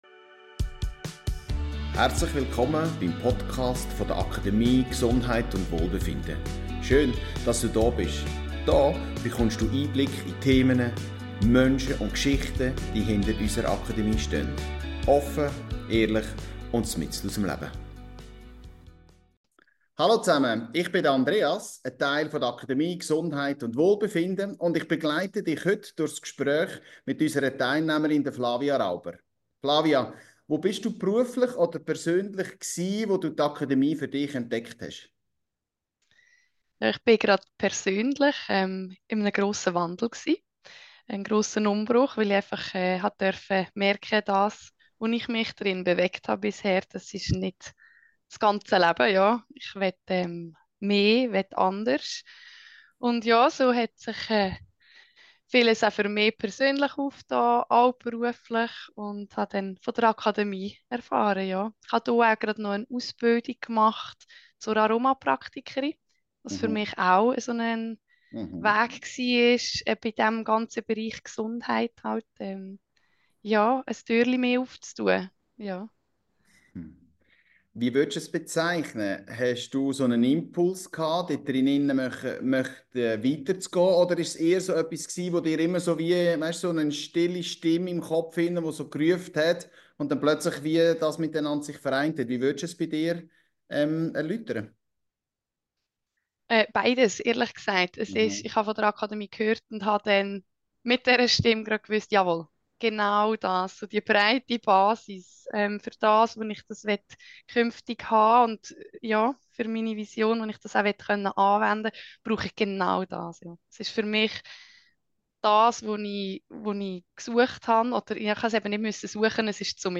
Ein Gespräch über Visionen, Zweifel, Alltagstauglichkeit – und den Mut, sich selbst ernst zu nehmen.